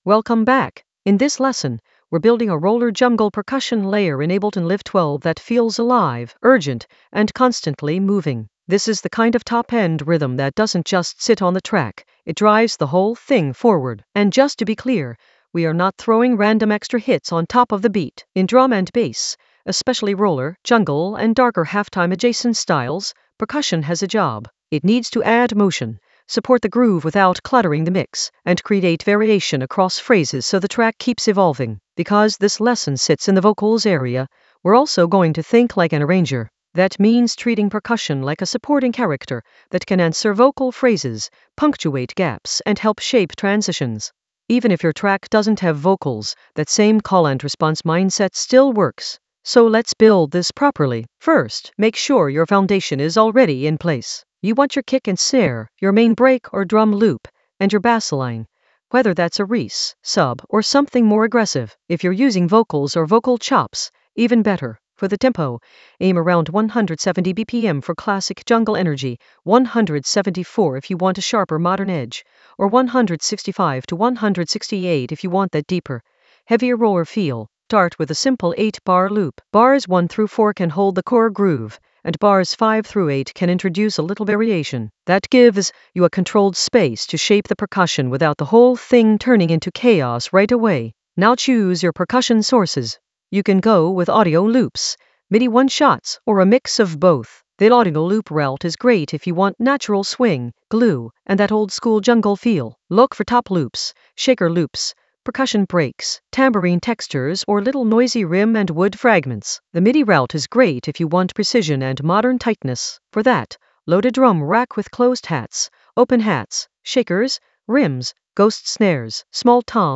Narrated lesson audio
The voice track includes the tutorial plus extra teacher commentary.
An AI-generated intermediate Ableton lesson focused on Roller jungle percussion layer: blend and arrange in Ableton Live 12 in the Vocals area of drum and bass production.